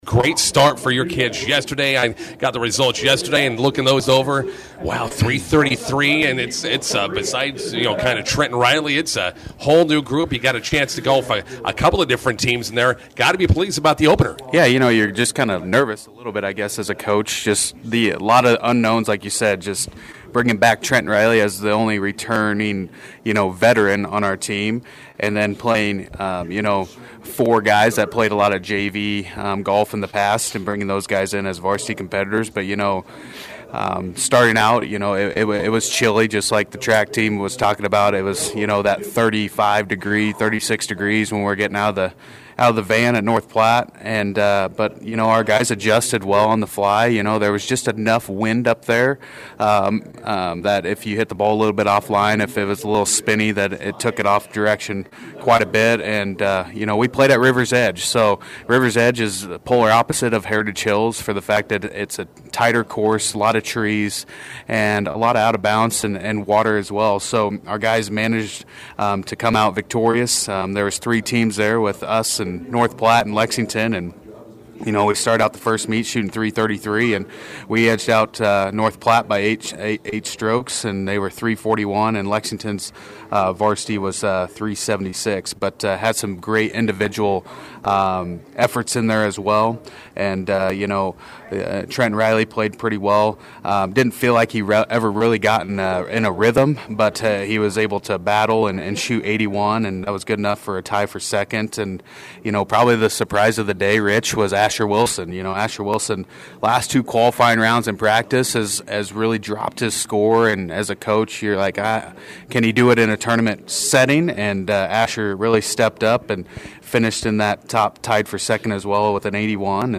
INTERVIEW: Bison boys golfers win North Platte Triangular on Friday.